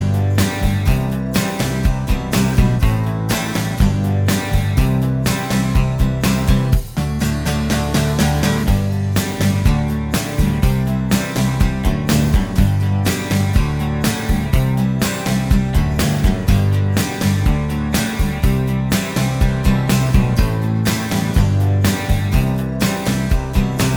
Minus Guitars Indie / Alternative 2:41 Buy £1.50